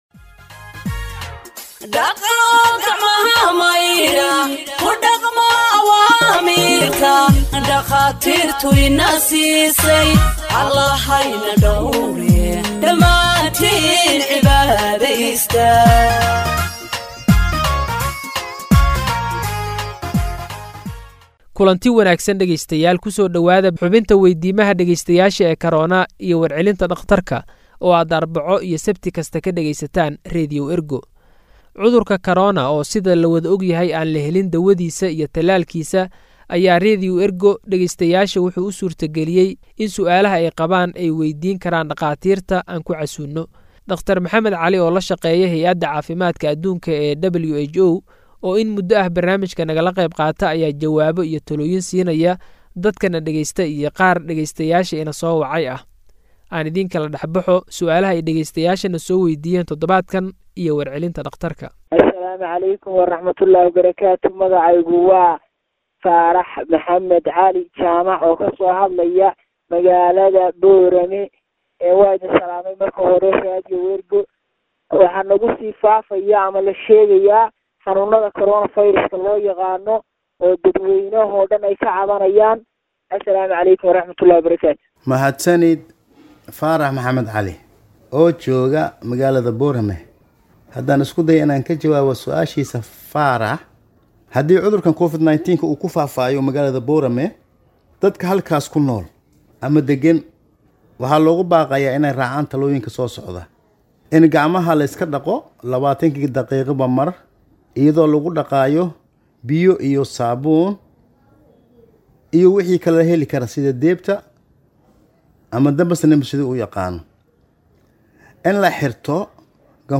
Health expert answers listeners’ questions on COVID 19 (31)
Radio Ergo provides Somali humanitarian news gathered from its correspondents across the country for radio broadcast and website publication.